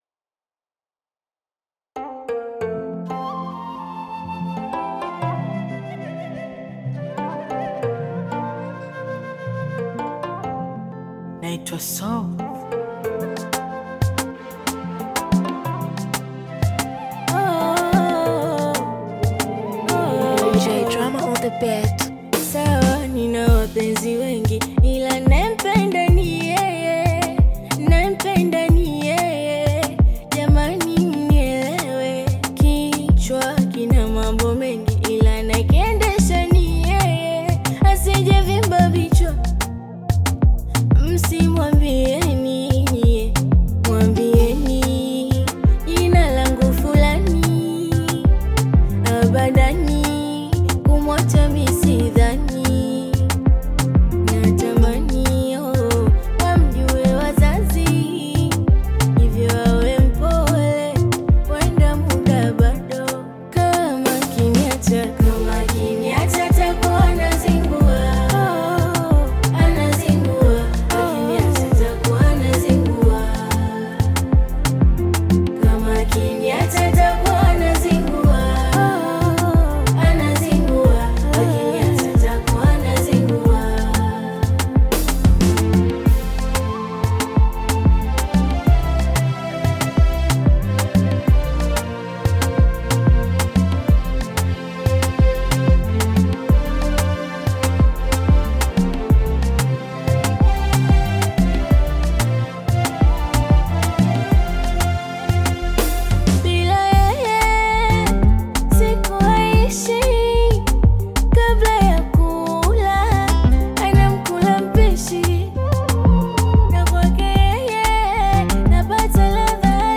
Tanzanian Bongo Flava artist, singer and songwriter
Bongo Flava You may also like